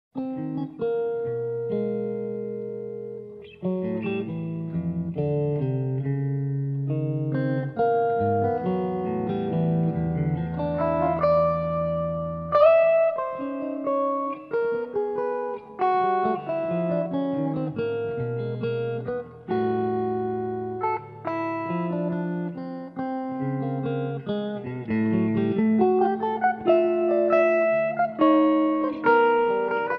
Guitars
Bass
Drums